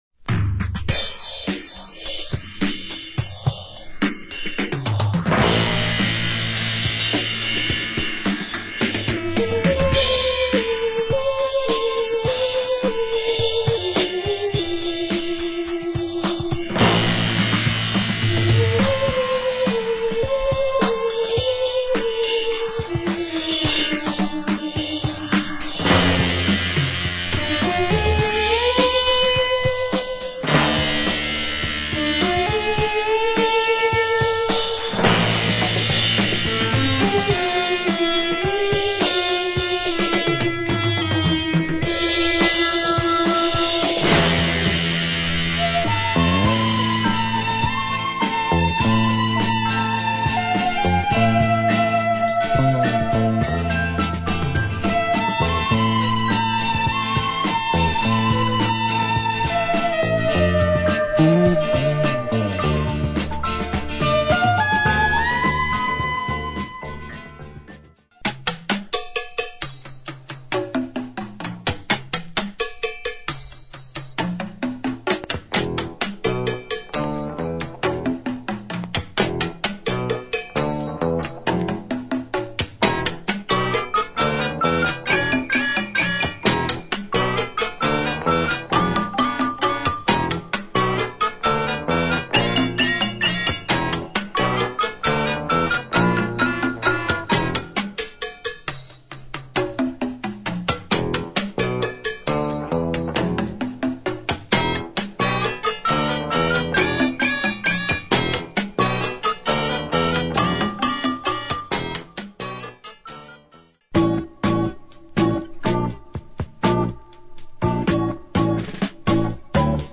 crazy flanged drumbreaks and moog groove.